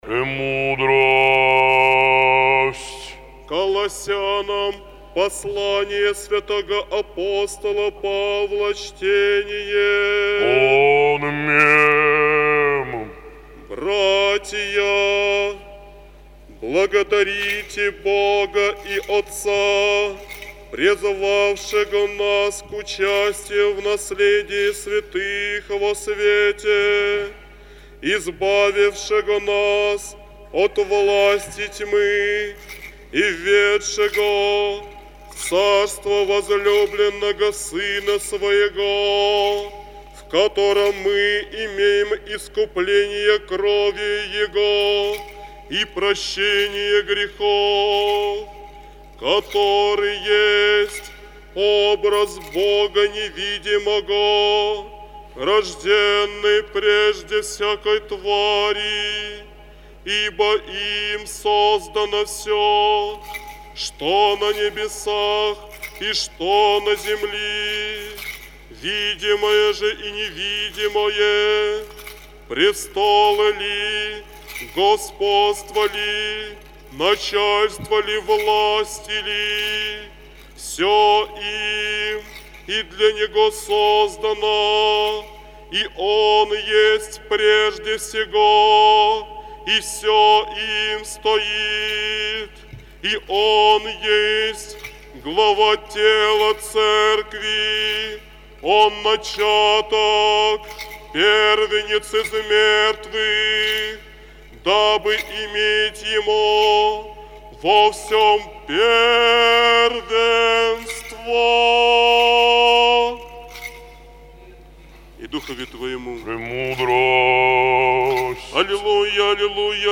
апостольское ЧТЕНИЕ